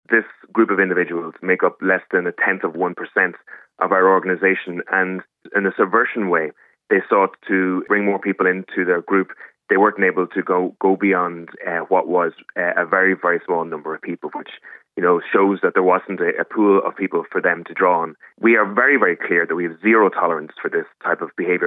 Speaking to Kfm, he described the situation as “heartbreaking,” particularly to see young people’s hearts “hardened by radical online social media content.”